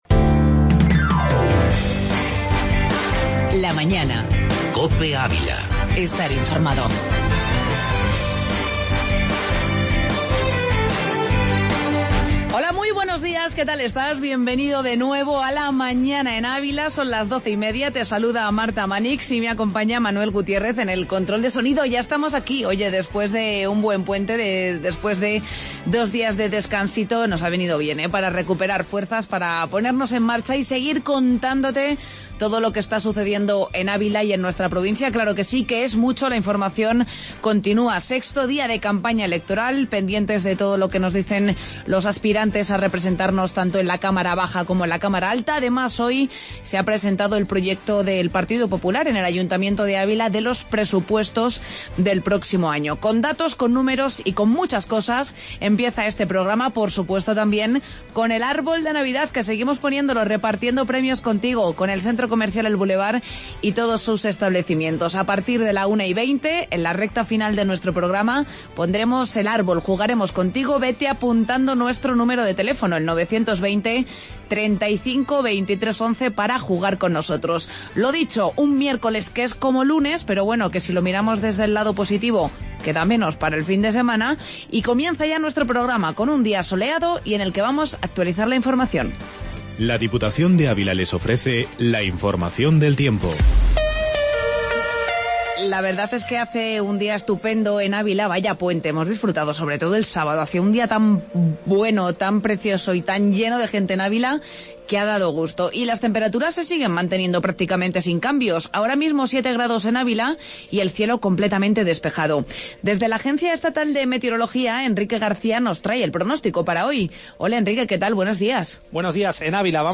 AUDIO: Entrevista Agustín Díaz de Mera y Tertulia Deportiva con Navas Center